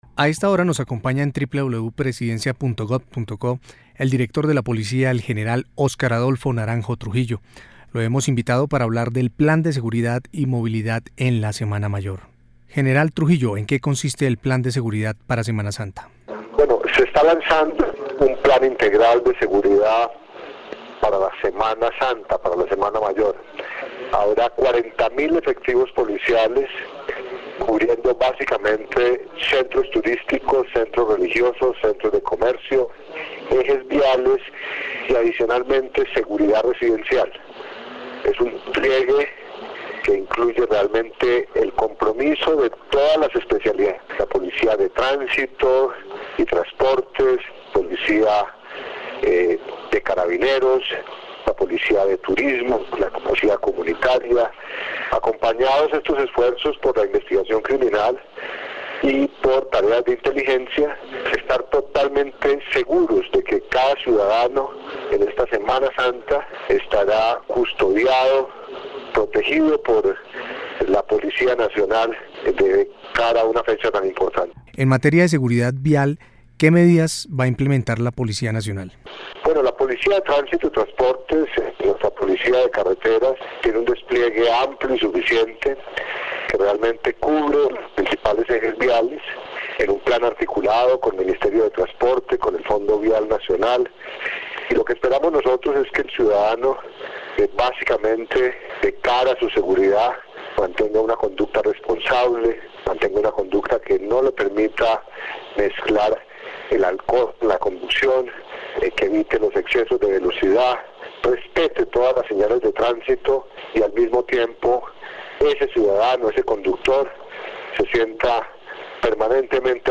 Voz General Óscar Adolfo Naranjo Trujillo